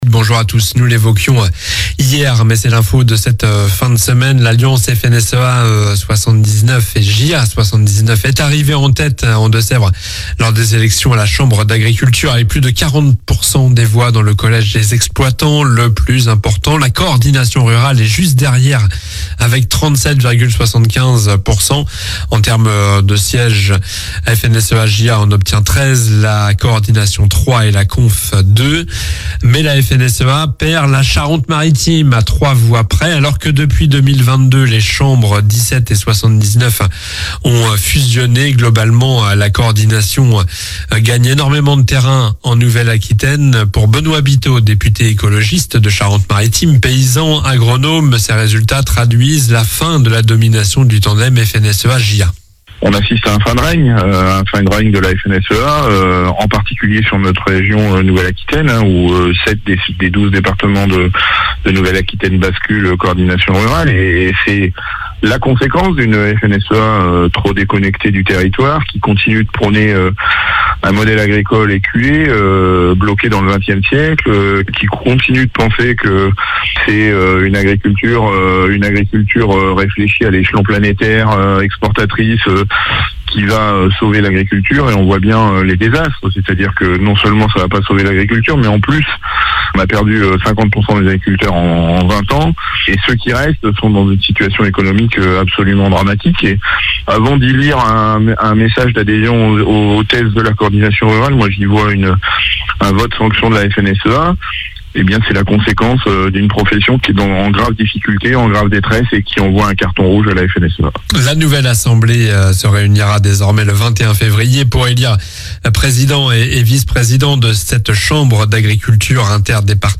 Journal du samedi 08 février (matin)